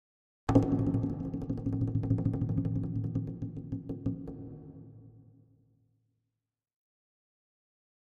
Drums Percussion Danger - Fast Drumming On A Medium Percussion 4